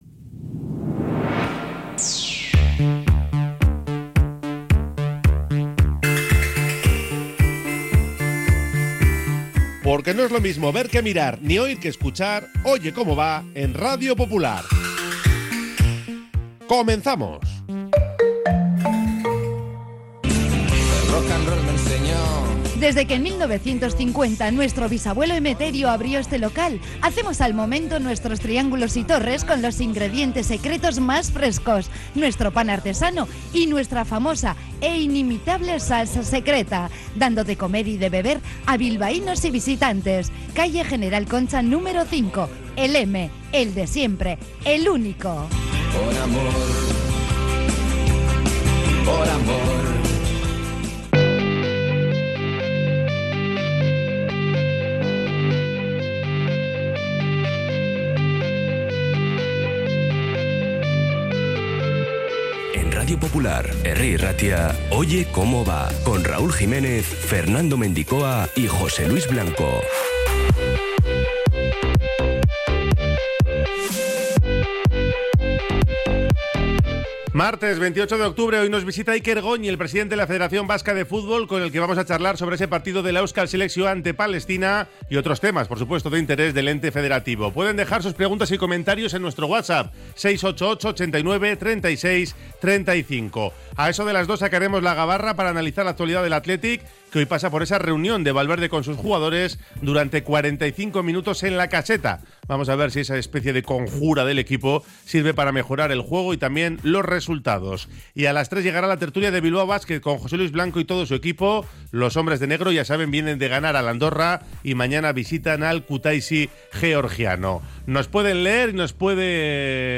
Oye Cómo Va 28-10-25 | Entrevista